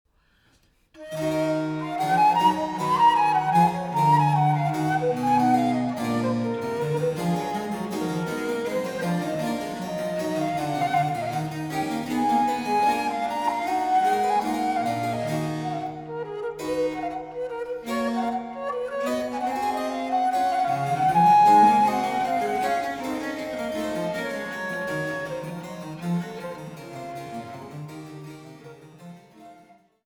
Traversflöte
Courante